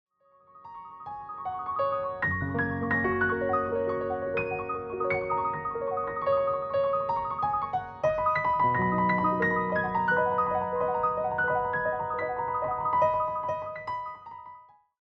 ” where bright, dancing passages evoke childhood excitement.